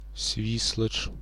1. ^ Belarusian: Свiслач, romanizedSvislač, IPA: [ˈɕvislatʃ]